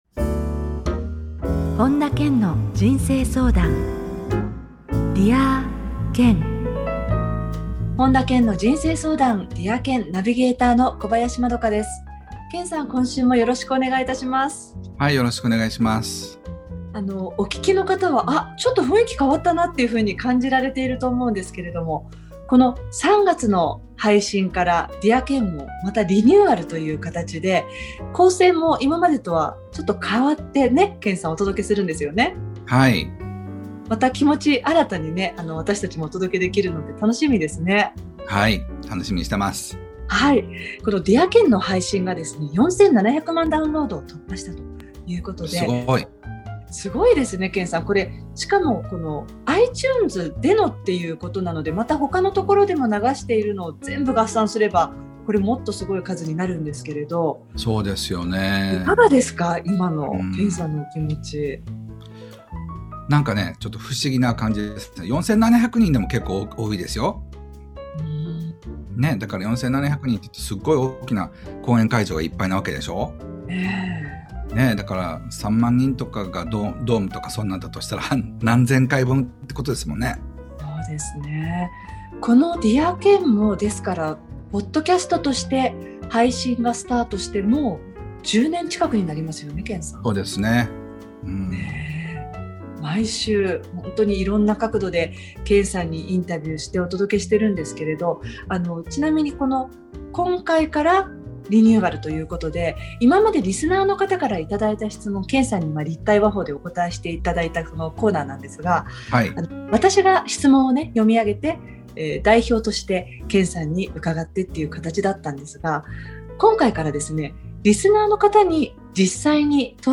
今週からDear Kenの内容をリニューアルし、リスナーさんから直接、本田健に質問していただいた音源をお届けします。